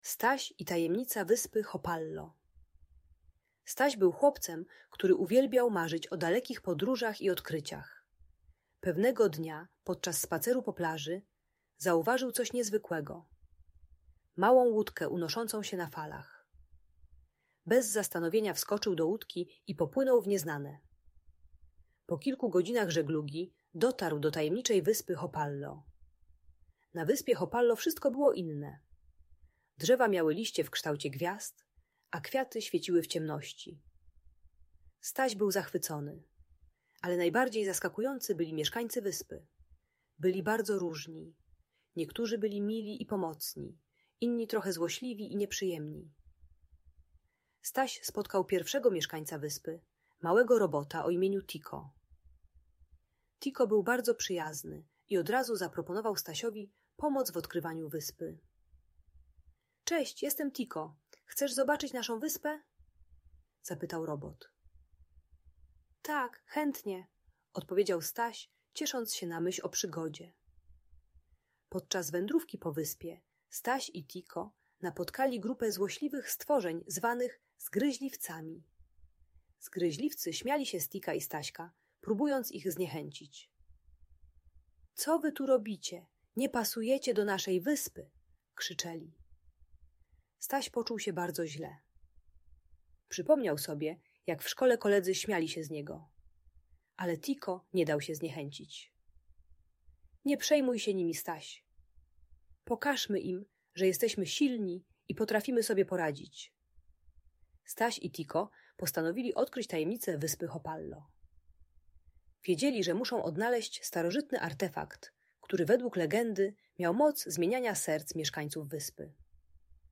Staś i Tajemnica Wyspy Hopallo - Szkoła | Audiobajka